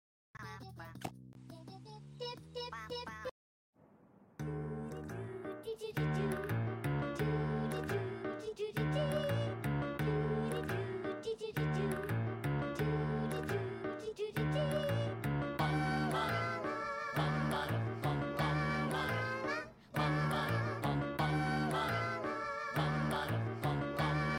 day 1 of recreating the dabloons sound and u just got 100 dabloons